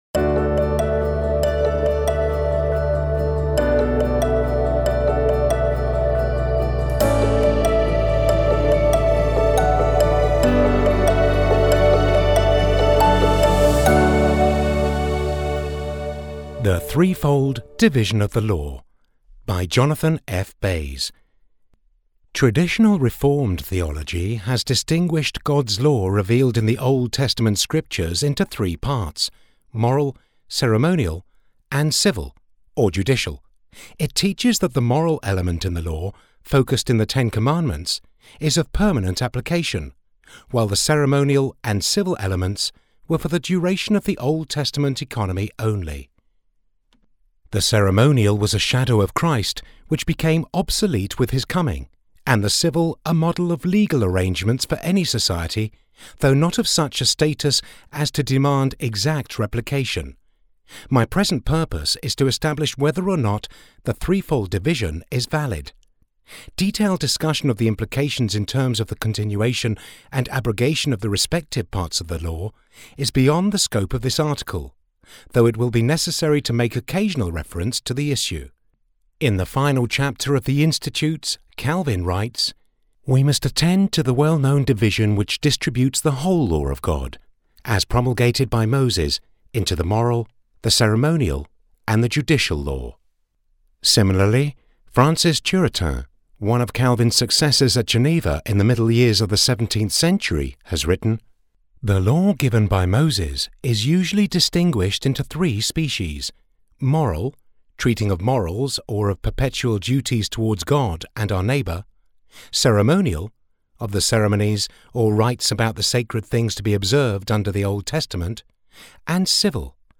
Audiobook Archives - The Christian Institute
threefold-division-audiobook.mp3